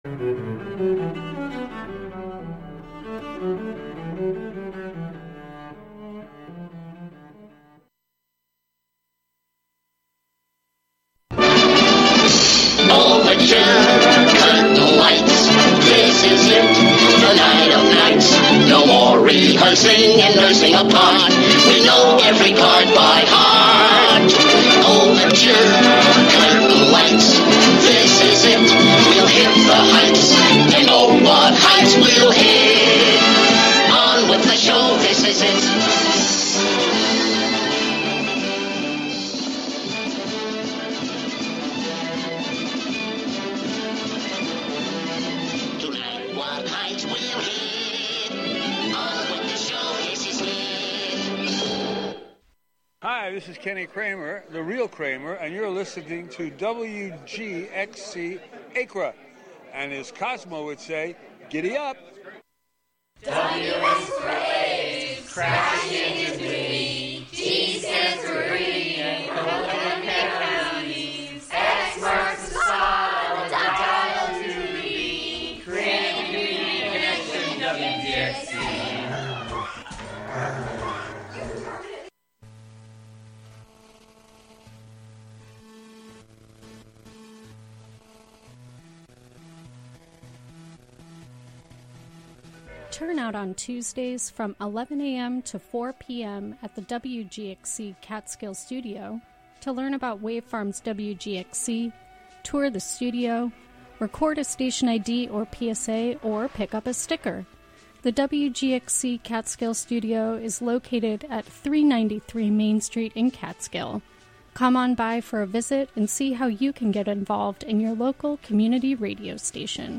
stops by the studio to talk about his online photography archive